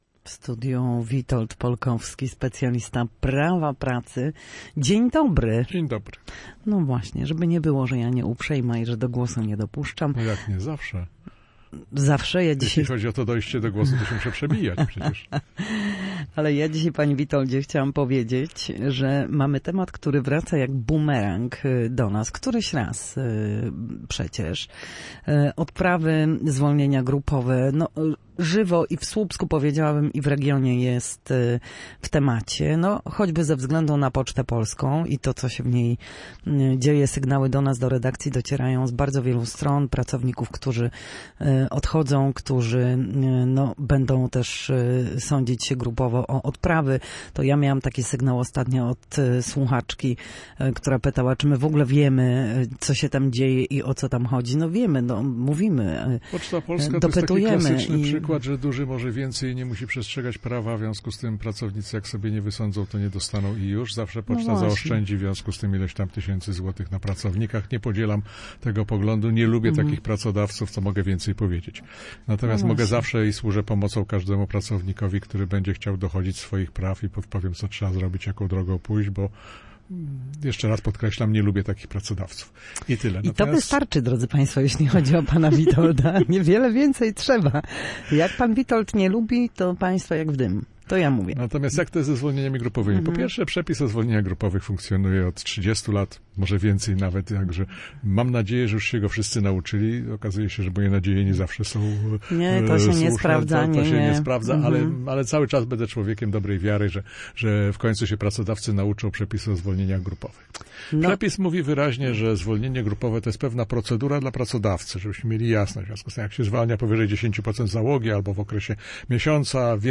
W każdy wtorek po godzinie 13:00 na antenie Studia Słupsk przybliżamy zagadnienia dotyczące prawa pracy.